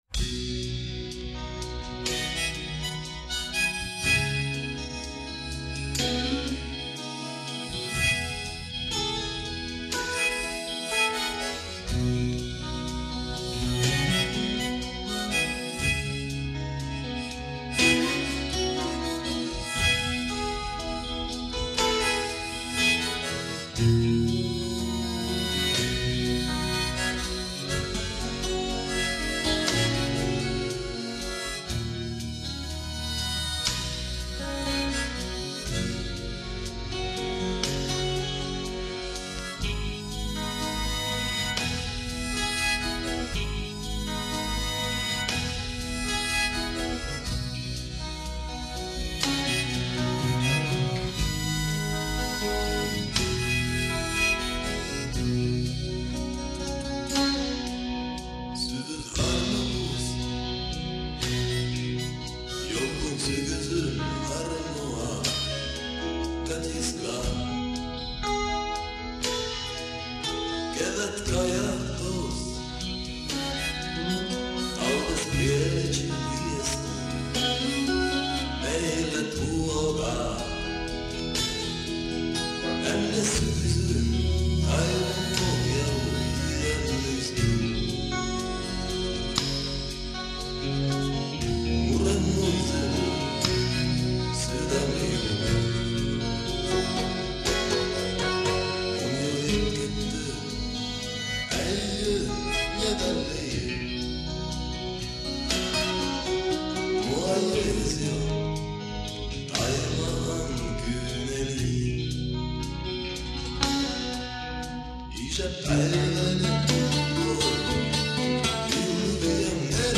Karjalankielistä rokkia Petroskoista
laulu, akustinen kitara
huilu, jouhikko, viulu
hanuri, huuliharppä
rummut
Digitaaliäänitys ja -miksaus: